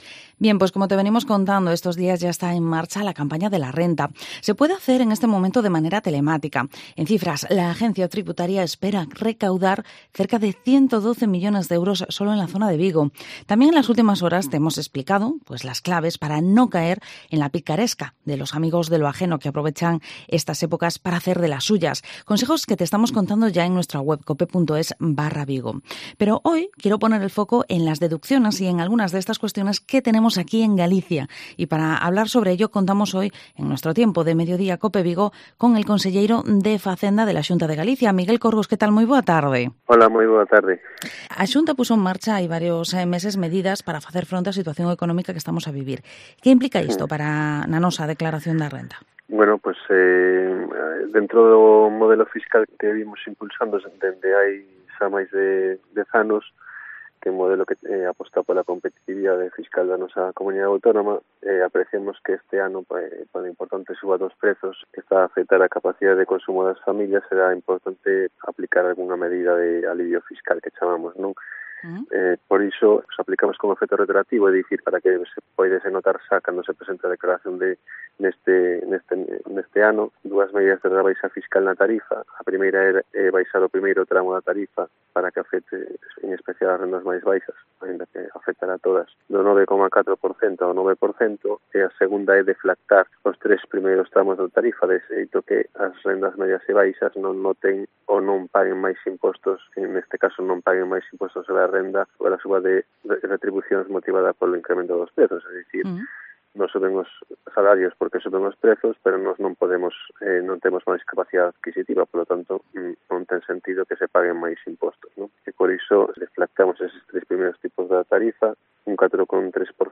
Entrevista al conselleiro de Facenda, Miguel Corgos en Cope Vigo